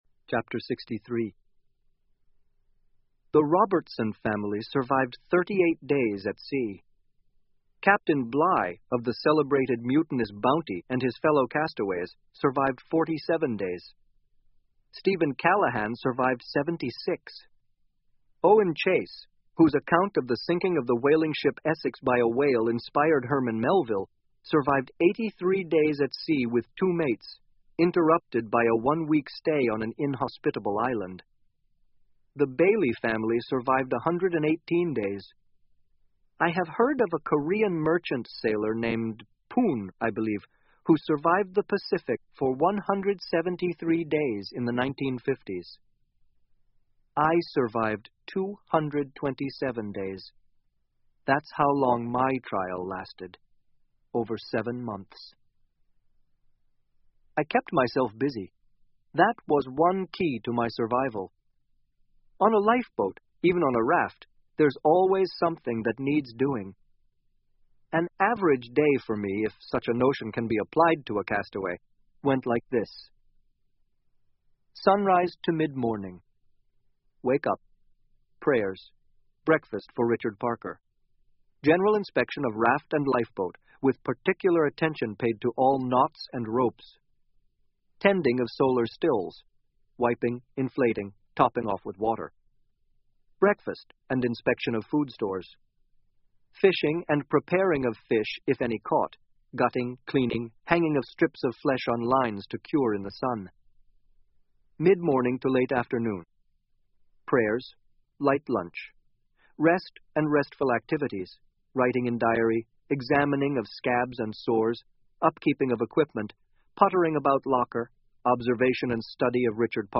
英文广播剧在线听 Life Of Pi 少年Pi的奇幻漂流 06-04 听力文件下载—在线英语听力室